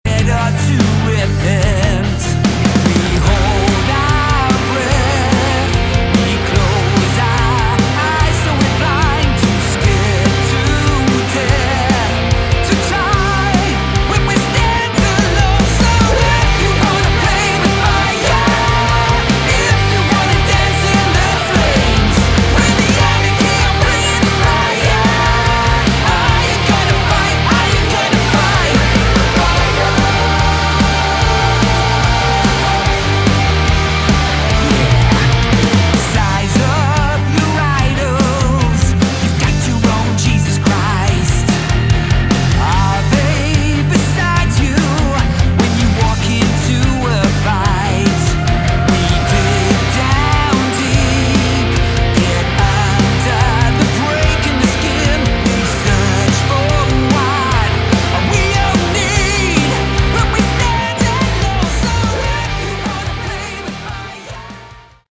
Category: Melodic Rock
vocals
bass
keyboards
guitars
drums